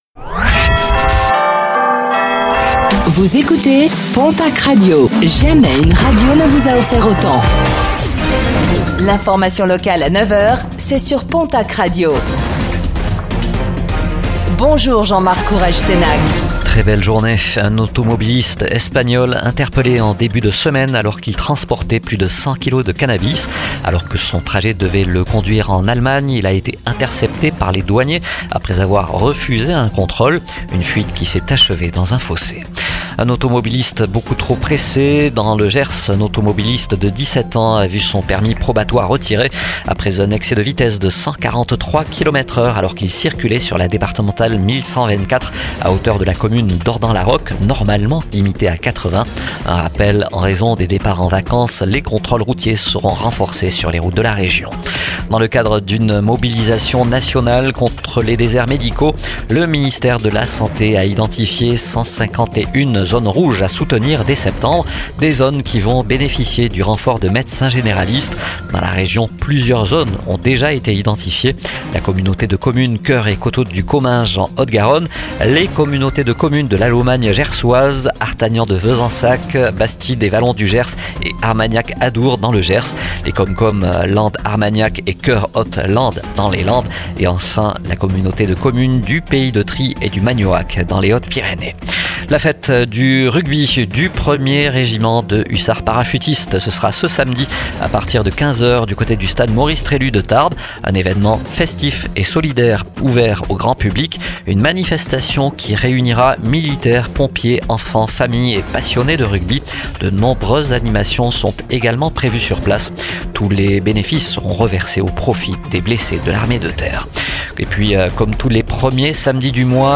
Réécoutez le flash d'information locale de ce vendredi 04 juillet 2025